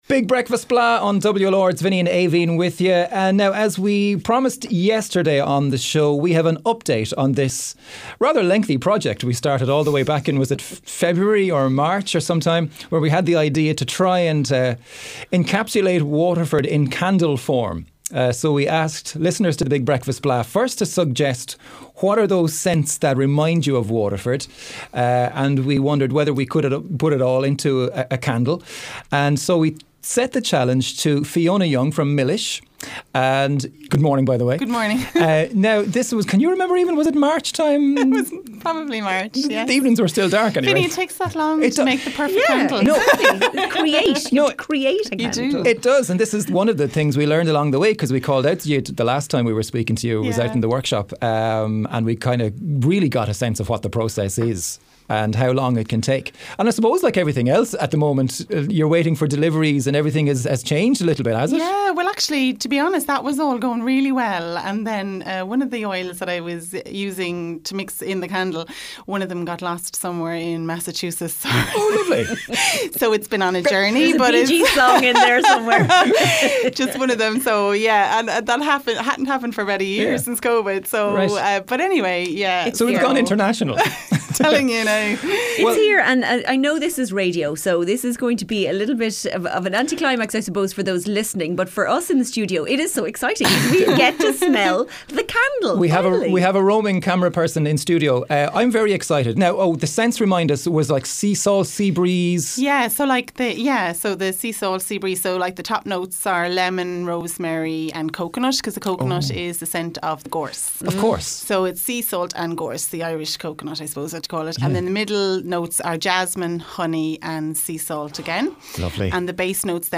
And there was much excitement in studio when the Big Breakfast Blaa presenters finally got to experience the scent for themselves.